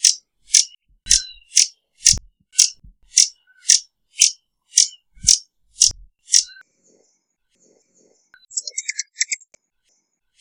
picaflorverde.wav